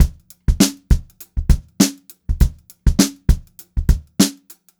100STBEAT2-R.wav